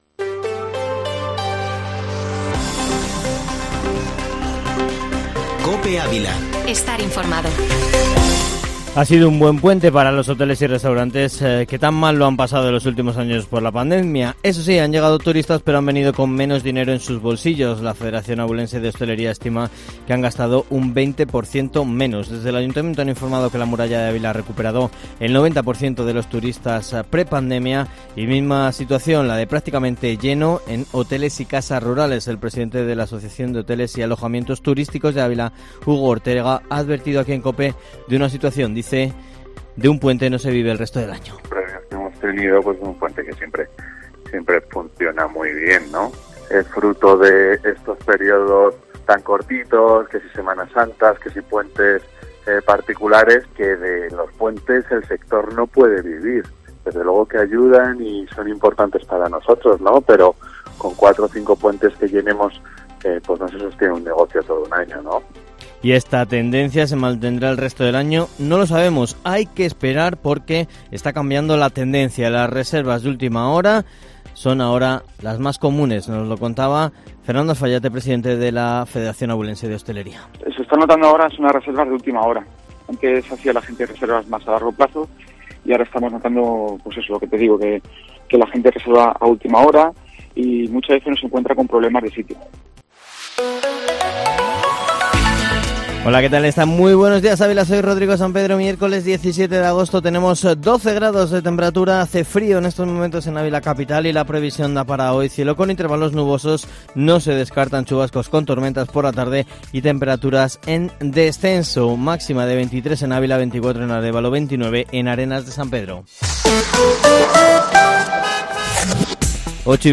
Informativo Matinal Herrera en COPE Ávila 17-agosto